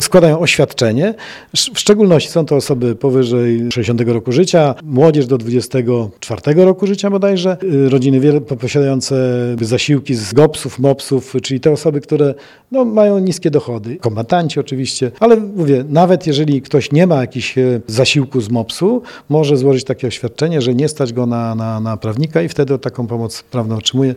– Pomoc skierowana jest do wszystkich, których nie stać na prawnika – mówi starosta, Marek Chojnowski.